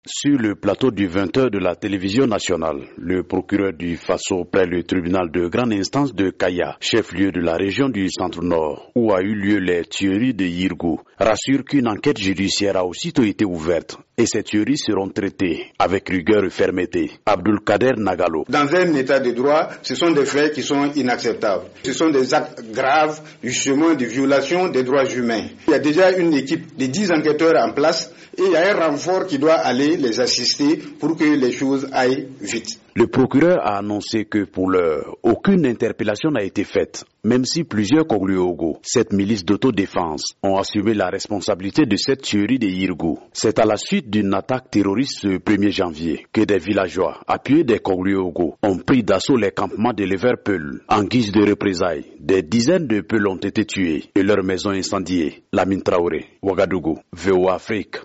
Au Burkina Faso, le procureur Abdoul Kader Nagalo, a fait hier soir le point après les tueries de Yirgou dans le Centre-Nord - tueries qui, le jour de l’an, ont fait 47 morts, des éleveurs peuls, pour la plupart. De Ouagadougou